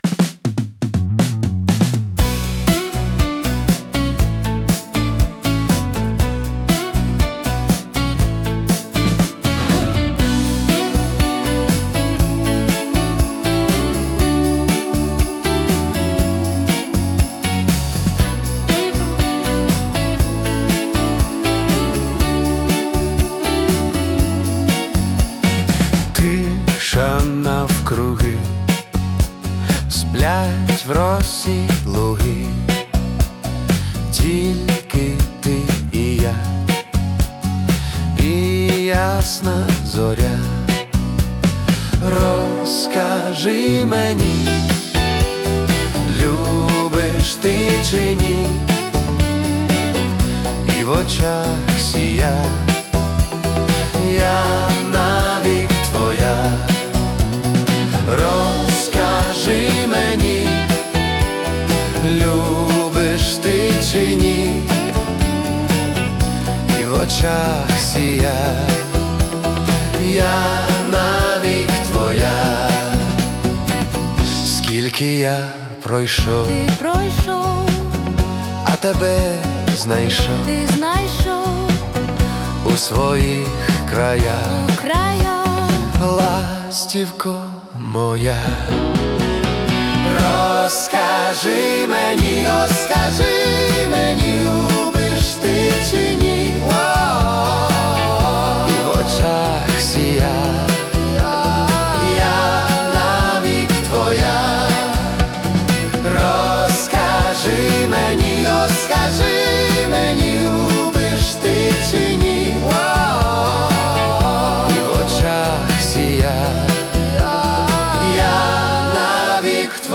🎵 Жанр: Retro Pop / VIA Style
• Налаштував тональність, щоб голос звучав ідеально чисто.